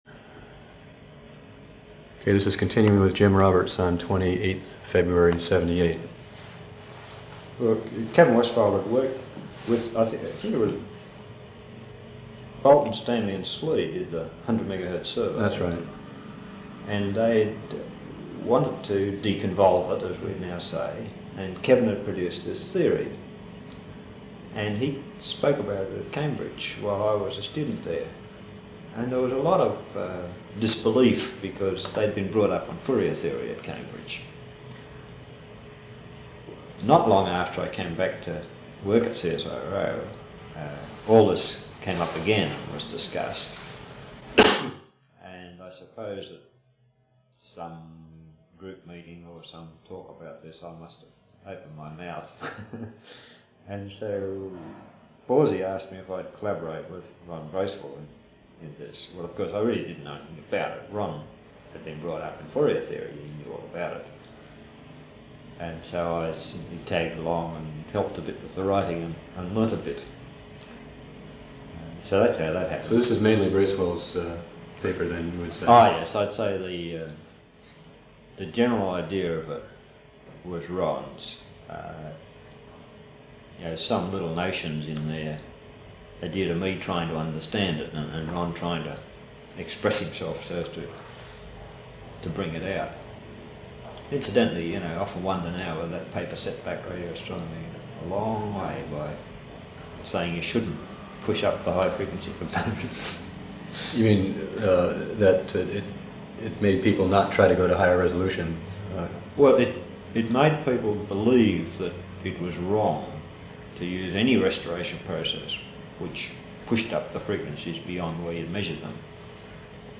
Type Oral History
Location Sydney, Australia Original Format of Digital Item Audio cassette tape